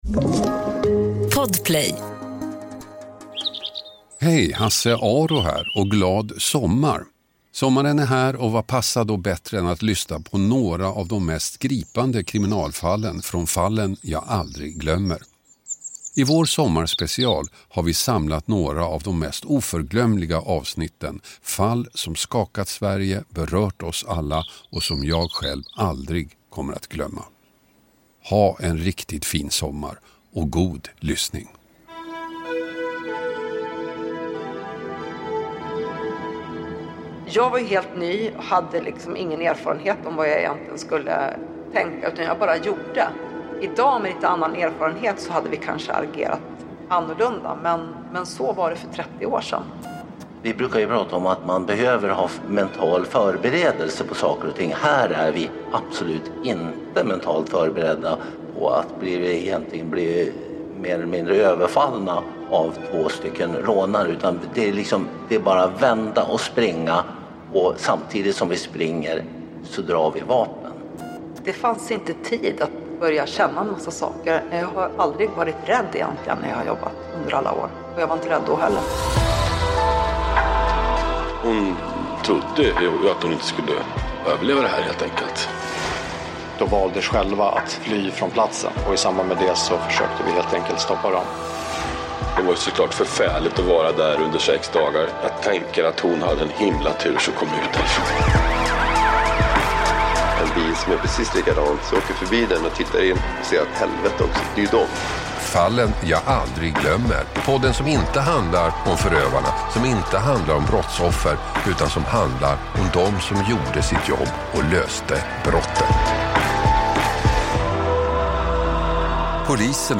Hasse Aro intervjuar poliserna som för första gången berättar om rånet, skottlossningen, gripandet och de spår som satt sig i dom båda efter en minst sagt dramatisk händelse i bägges liv.